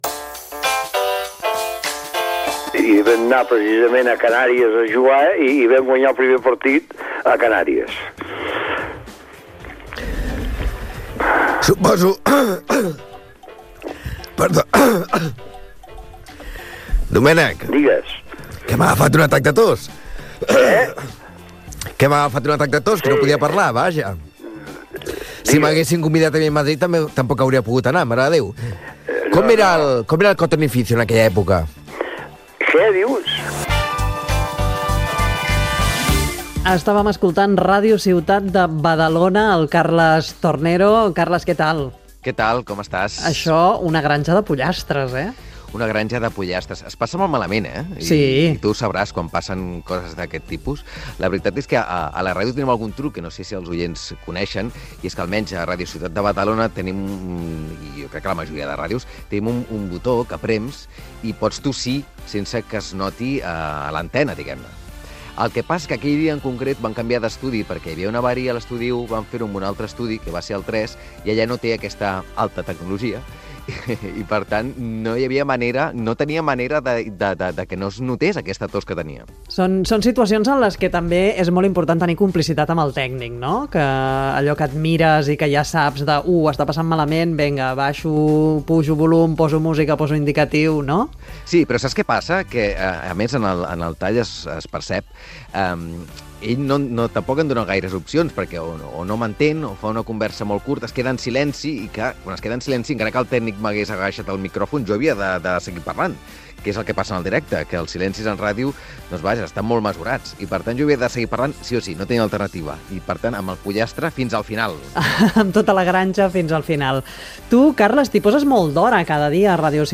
entrevista
Entreteniment
FM